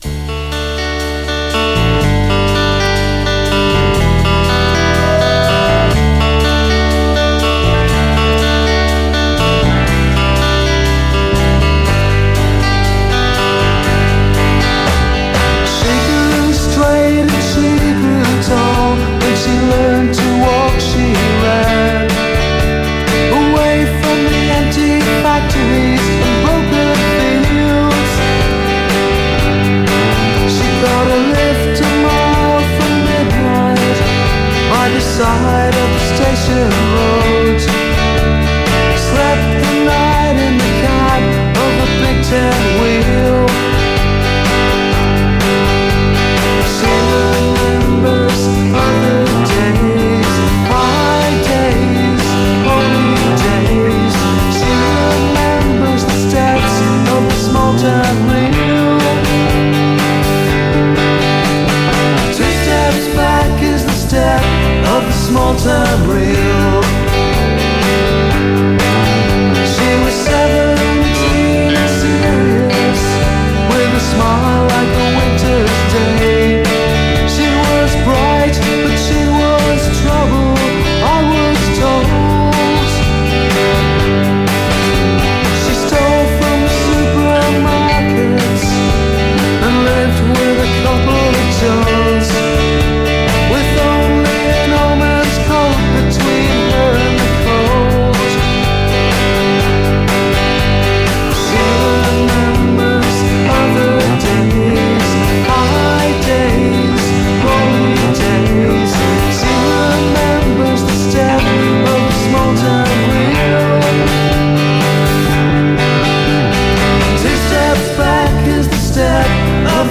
Sounds of Jangle-1980s.
vocals, guitar
bass guitar
drums